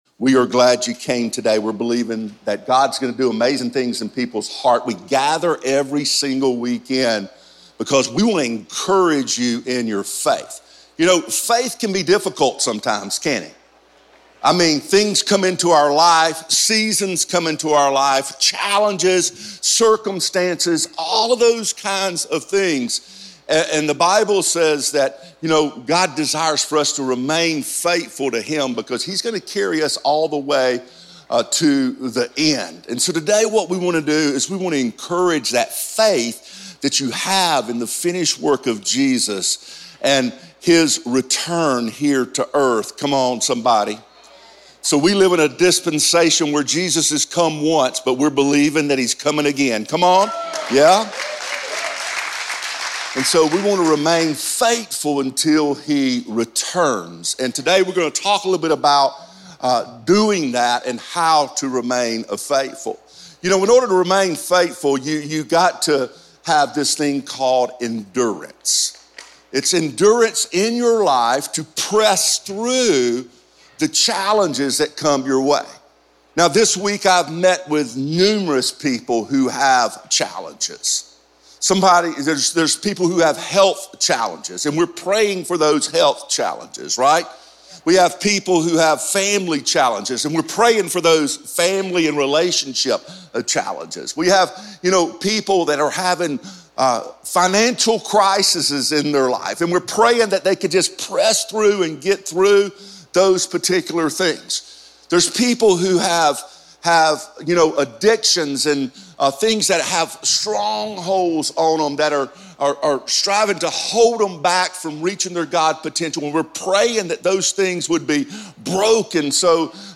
a dynamic, high-energy speaker with a heart and vision to reach the world.